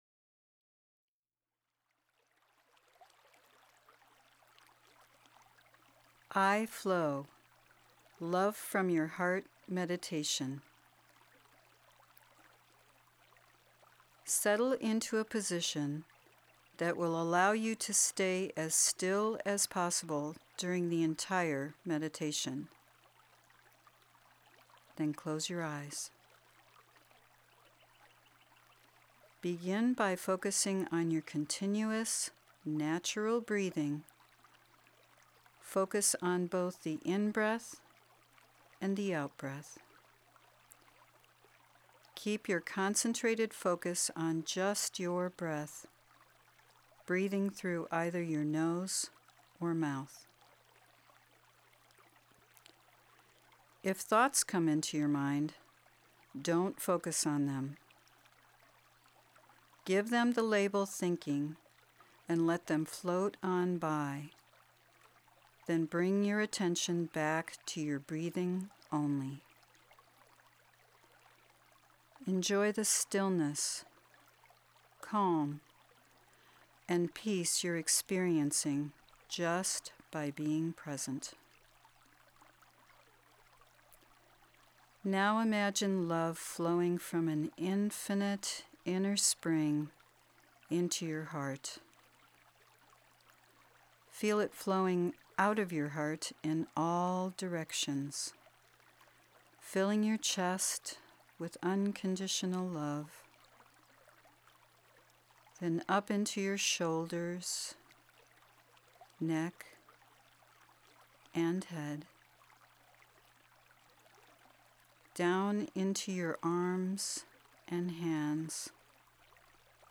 I-Flow Heart Meditation